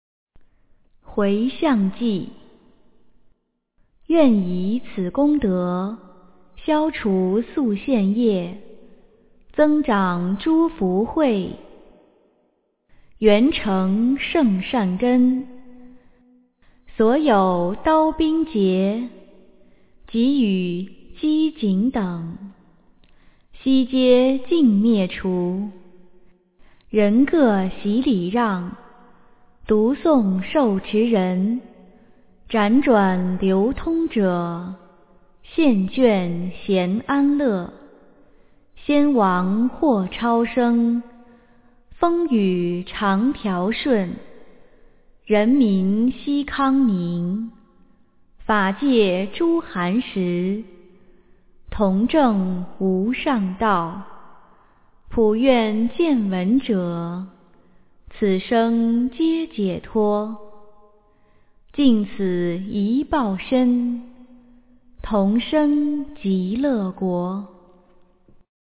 诵经
佛音 诵经 佛教音乐 返回列表 上一篇： 心经(念诵